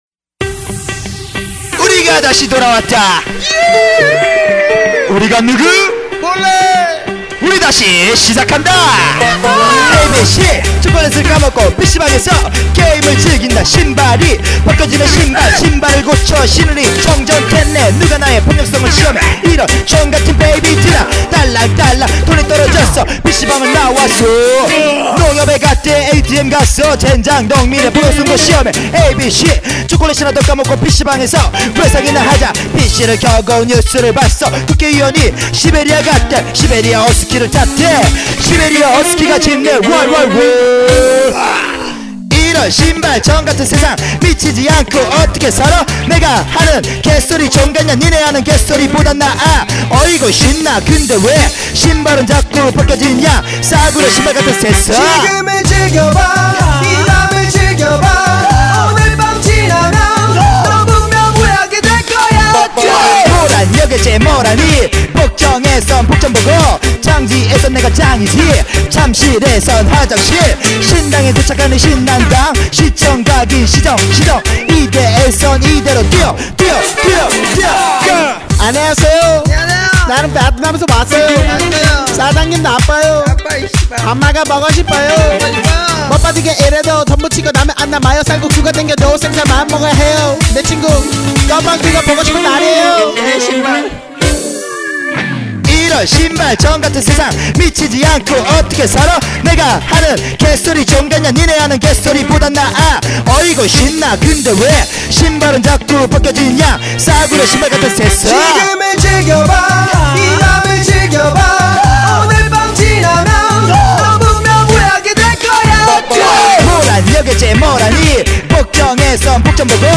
• [국내 / REMIX.]